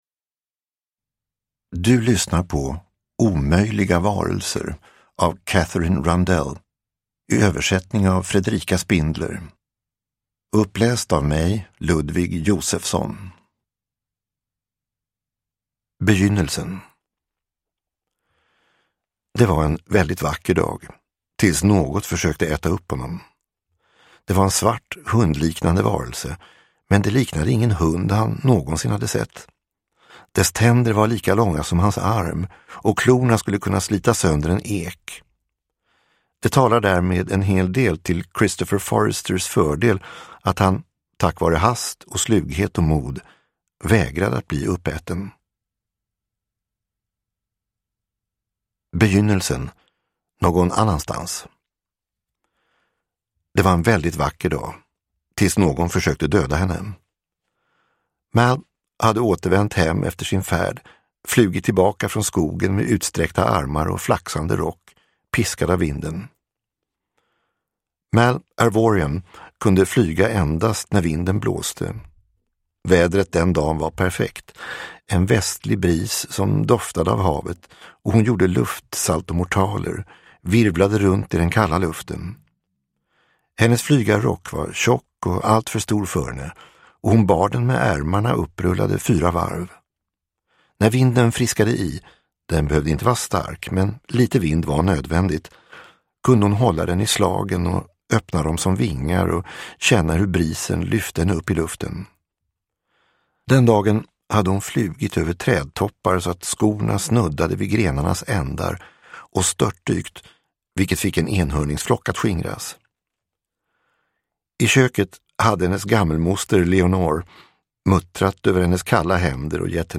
Omöjliga varelser – Ljudbok